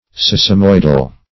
sesamoidal - definition of sesamoidal - synonyms, pronunciation, spelling from Free Dictionary Search Result for " sesamoidal" : The Collaborative International Dictionary of English v.0.48: Sesamoidal \Ses`a*moid"al\, a. (Anat.)
sesamoidal.mp3